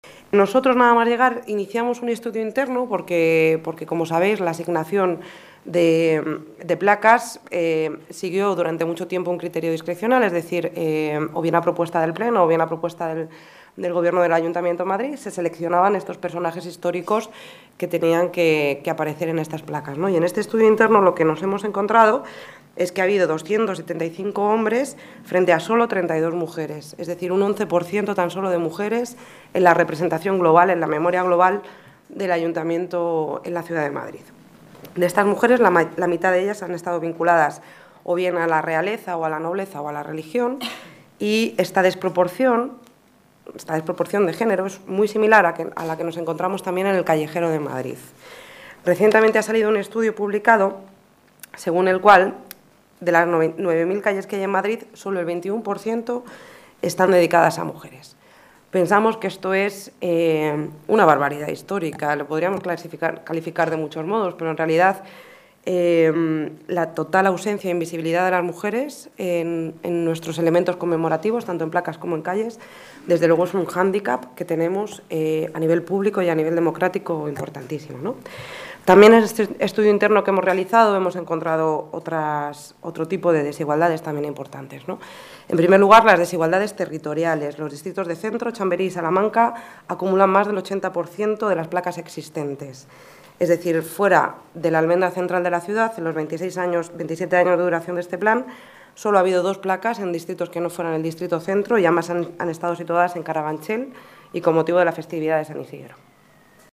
Nueva ventana:Celia Mayer habla del homenaje a las mujeres de la Generación del 27